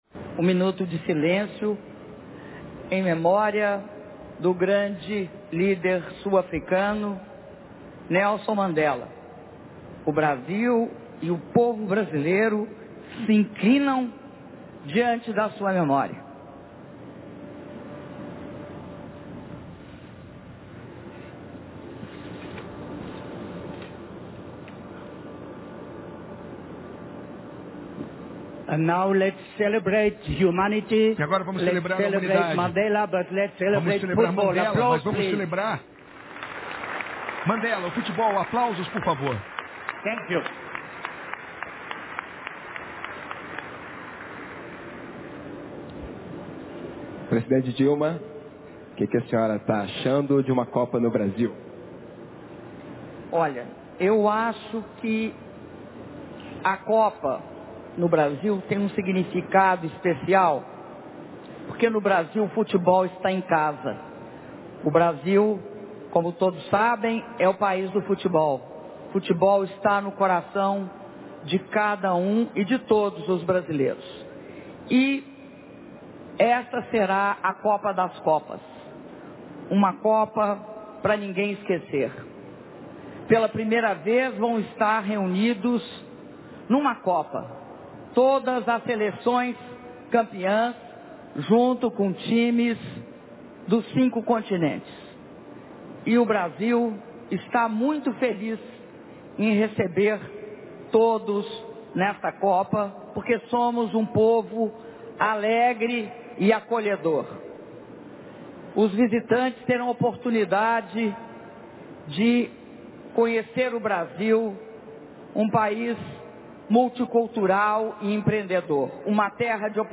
Áudio das palavras da Presidenta da República, Dilma Rousseff, na cerimônia de Sorteio Final da Copa do Mundo FIFA Brasil 2014